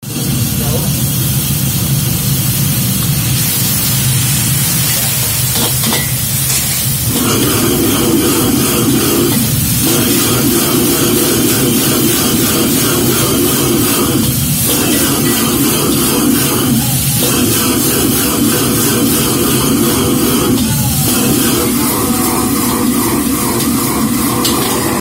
Âm thanh Trộn, Xào, Nấu… đồ ăn trên Chảo lớn ở Nhà hàng
Thể loại: Tiếng đồ vật
am-thanh-tron-xao-nau-do-an-tren-chao-lon-o-nha-hang-www_tiengdong_com.mp3